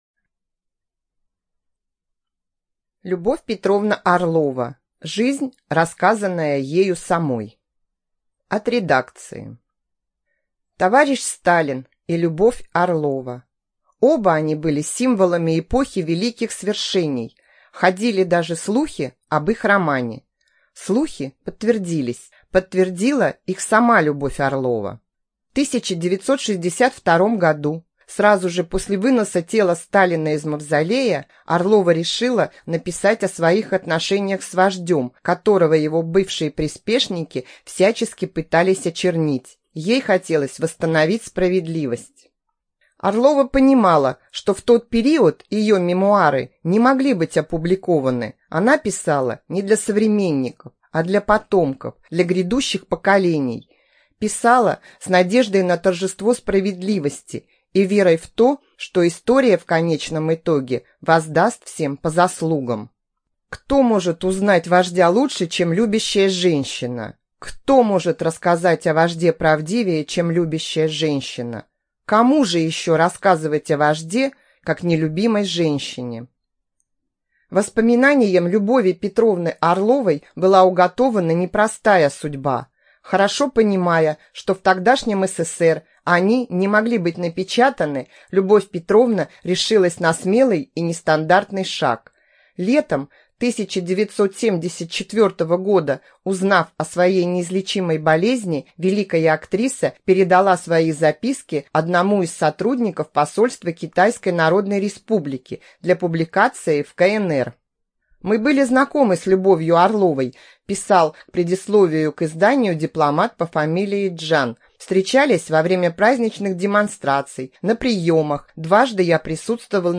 ЖанрБиографии и мемуары